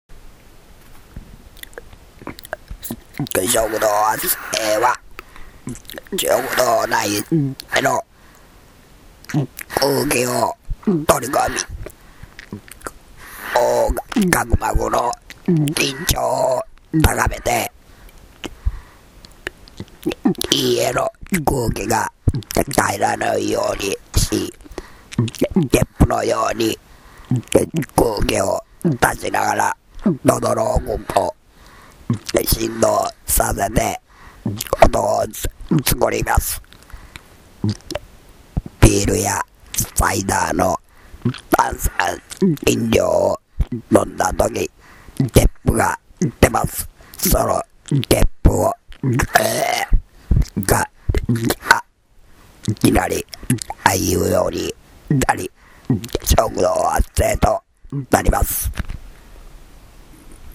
ゲップのように空気を出しながら喉の奥も振動させて音を作ります。